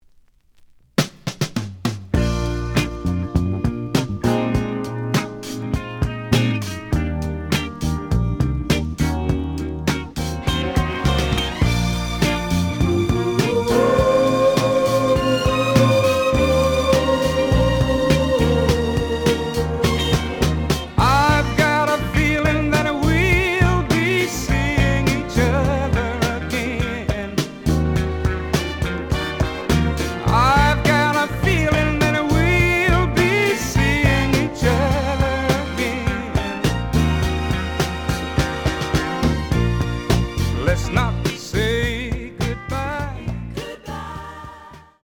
試聴は実際のレコードから録音しています。
●Genre: Soul, 70's Soul